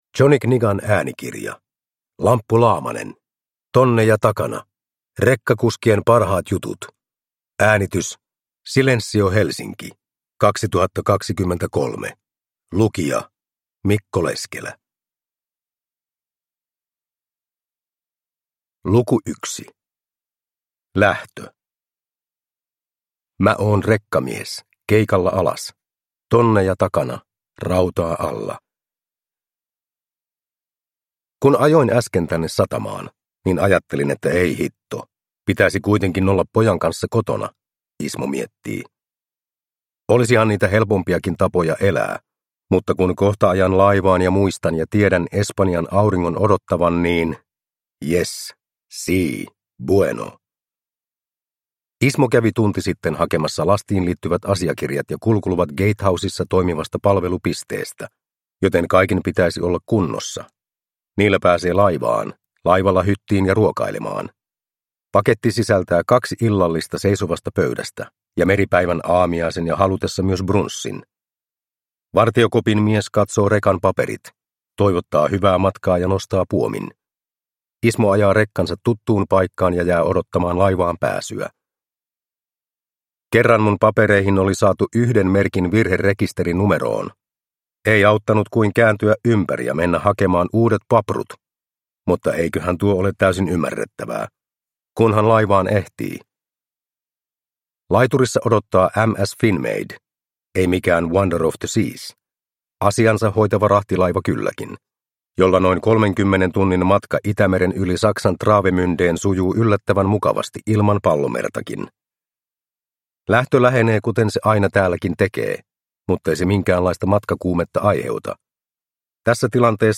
Tonneja takana (ljudbok) av Lamppu Laamanen